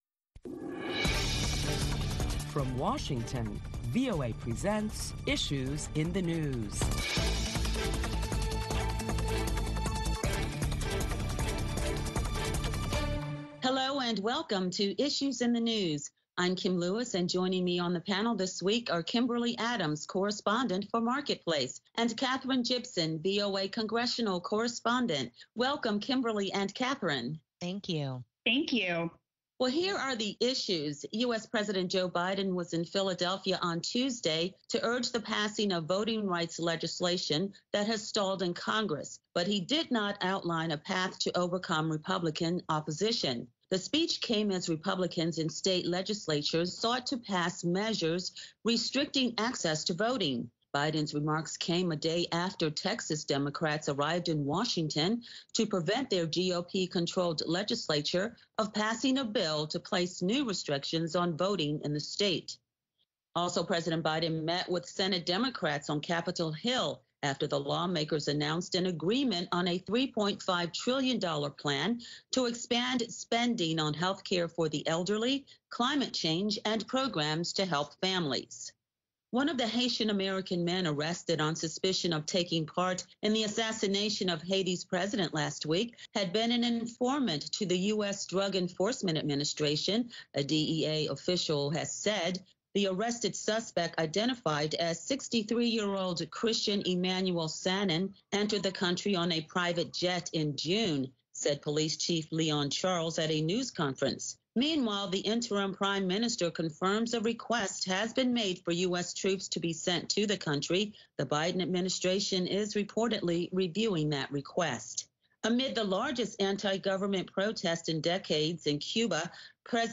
Issues in the News moderator